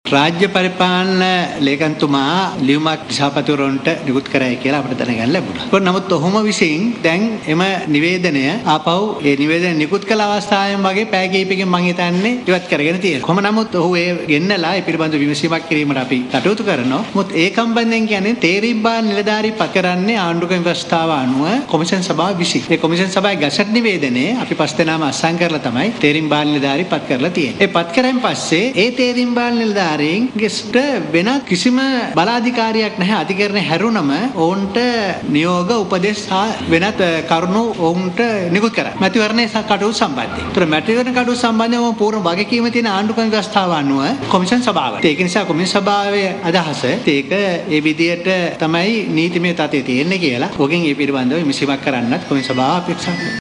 මේ ඒ පිළිබදව පසුගියදා පැවති විශේෂ මාධ්‍ය හමුවේ දී මැතිවරණ කොමිසමේ සභාපති නිමල් ජී පුංචි හේවා මහතා.